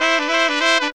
HORN RIFF 28.wav